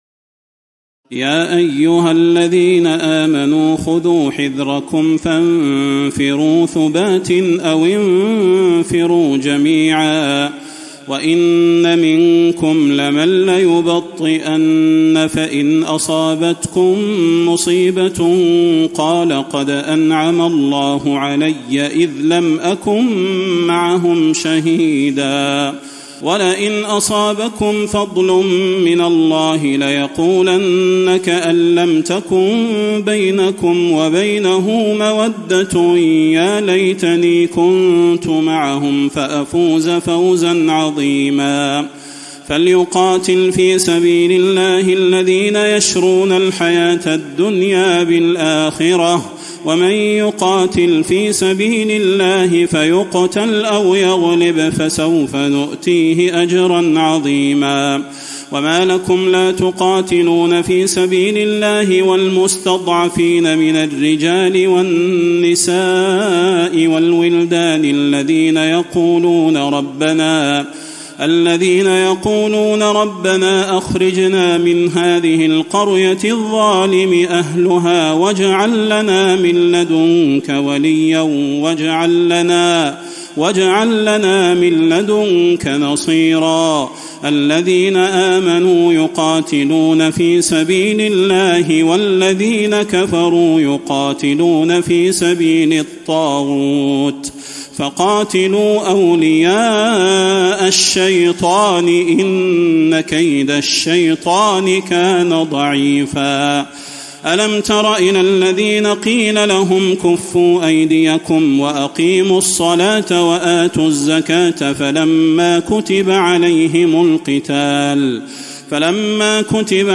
تراويح الليلة الخامسة رمضان 1435هـ من سورة النساء (71-141) Taraweeh 5 st night Ramadan 1435H from Surah An-Nisaa > تراويح الحرم النبوي عام 1435 🕌 > التراويح - تلاوات الحرمين